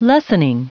Prononciation du mot lessening en anglais (fichier audio)
Prononciation du mot : lessening